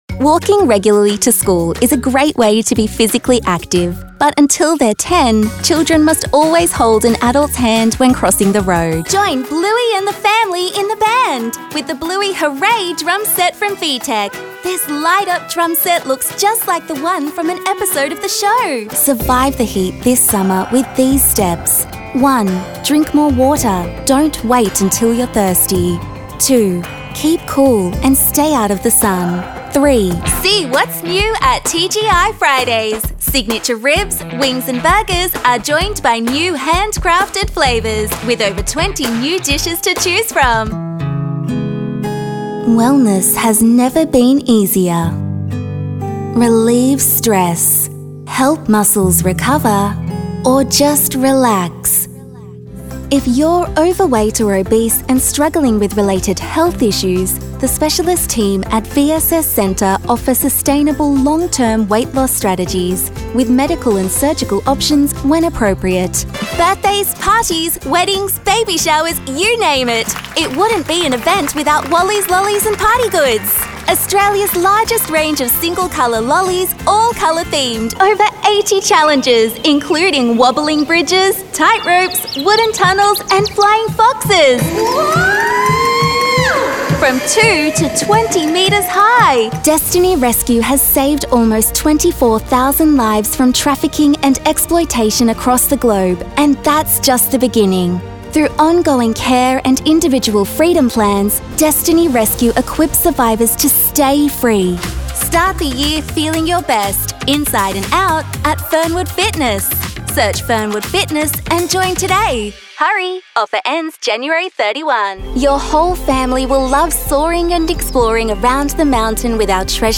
Female Voice Over Talent, Artists & Actors
Teenager (13-17) | Yng Adult (18-29)